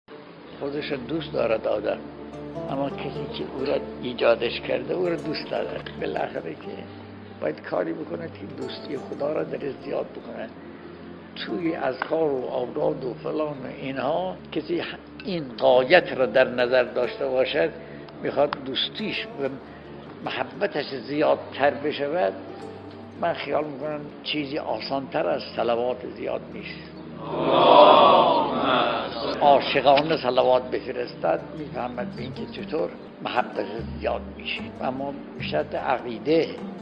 به گزارش خبرگزاری حوزه، مرحوم آیت الله بهجت در حاشیه درس خارج به موضوع «راهکار مؤثر برای رسیدن به کمال الهی» اشاره کردند که تقدیم شما فرهیختگان می شود.